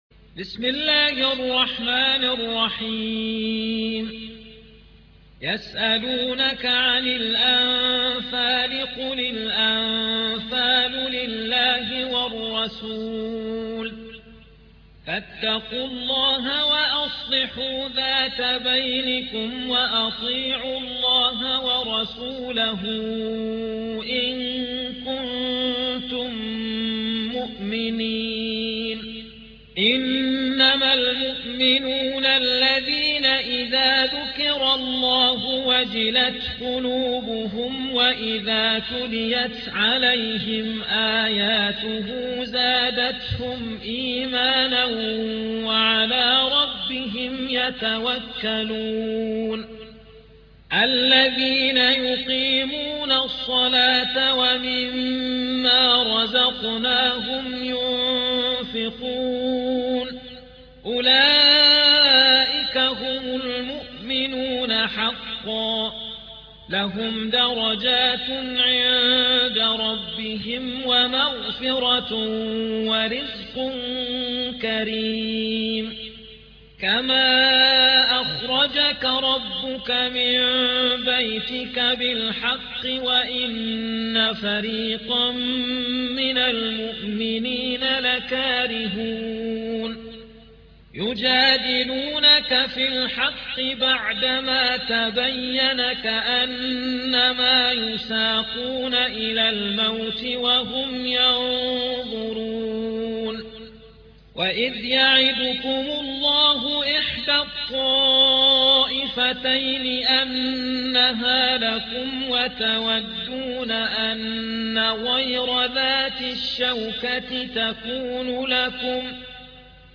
8. سورة الأنفال / القارئ